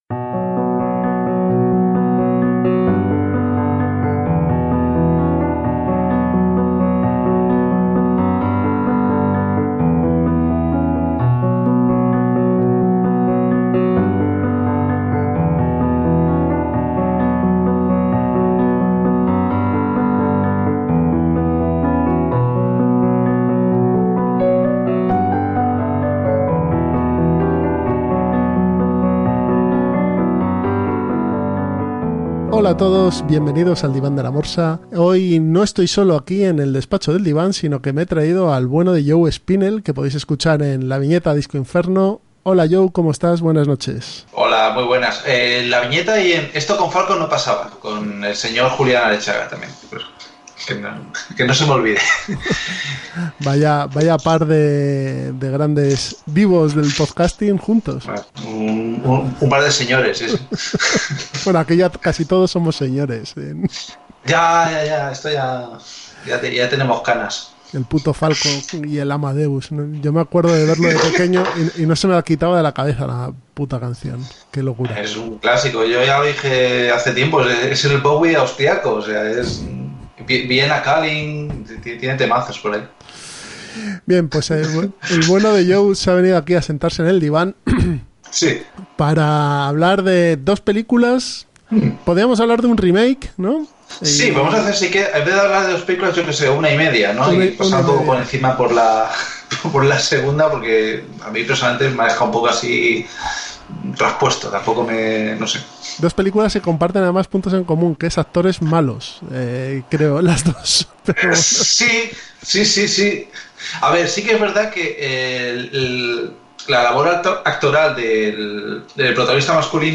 De manera desenfadada y con muchas risas narramos la película y damos nuestra opinión acerca de las dos versiones.